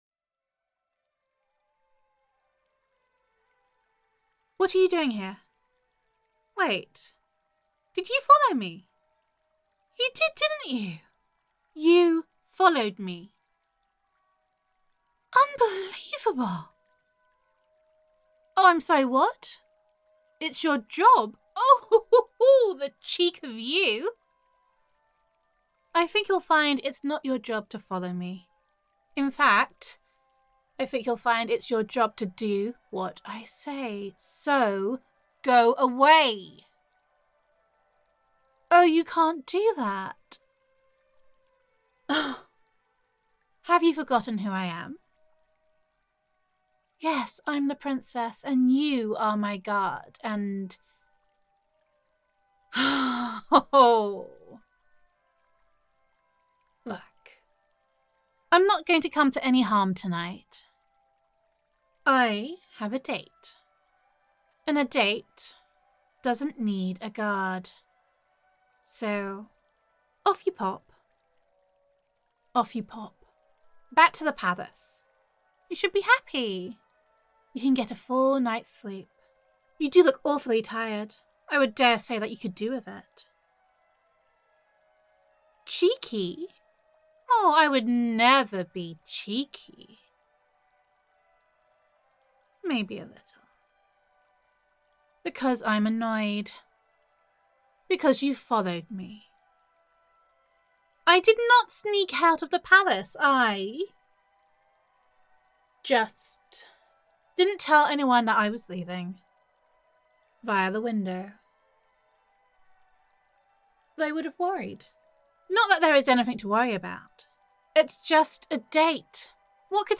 [F4A] Did You Follow Me? [Princess Roleplay][Personal Guard Listener][Love Confession][First Kiss][Plotting and Scheming][Jealousy][Valentine’s Day][Gender Neutral][Sometimes You Have to Get Sneaky When Your Personal Guard Is Love with You but Won’t Admit It]